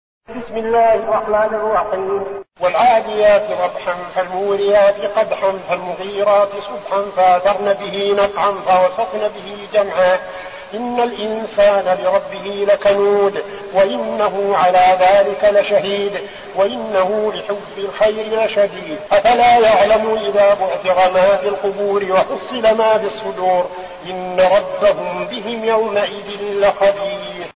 أحد أشهر قراء القرآن الكريم في العالم الإسلامي، يتميز بجمال صوته وقوة نفسه وإتقانه للمقامات الموسيقية في التلاوة.
تلاوات المصحف المجود